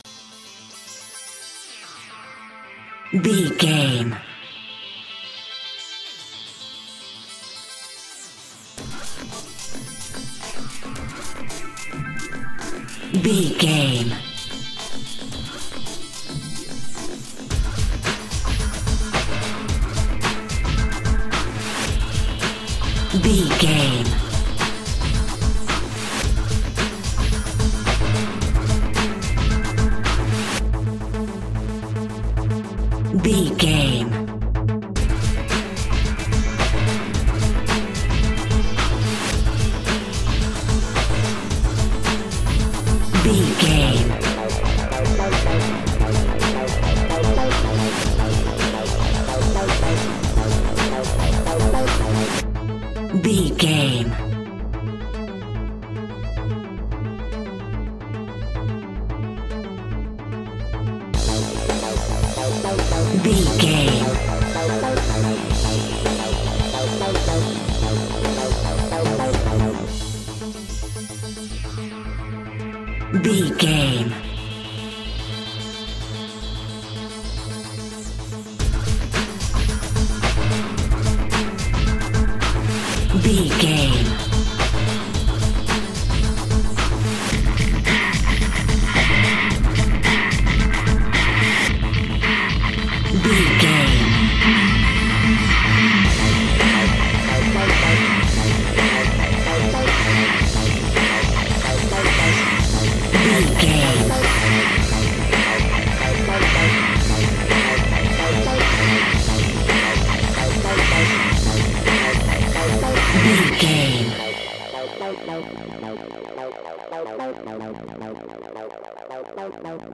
Fast paced
Aeolian/Minor
groovy
uplifting
futuristic
driving
energetic
repetitive
funky
drum machine
techno
trance
electronic music
synth drums
synth leads
synth bass